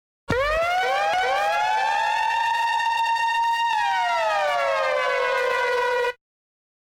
TM-88 FX #01.mp3